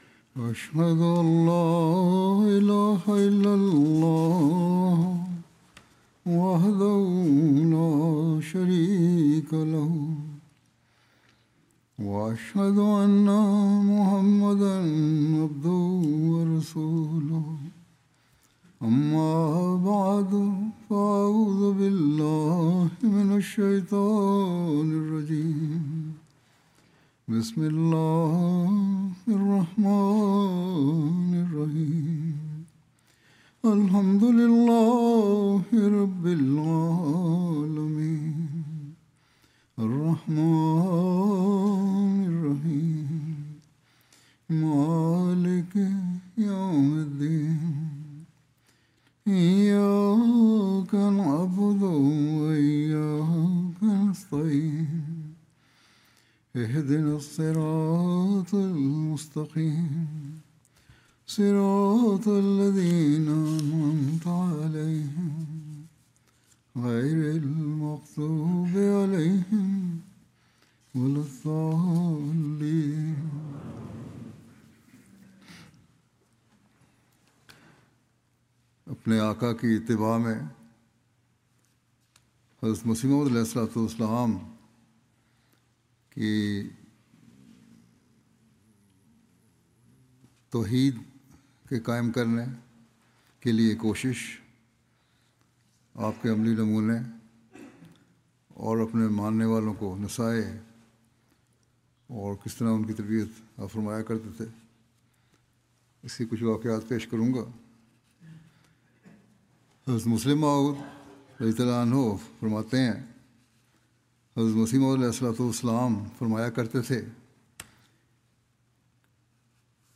Listen to Reflecting His Master (sa): The Promised Messiah's (as) Call to Tauheed from Urdu Friday Sermon by Head of Ahmadiyya Muslim Community.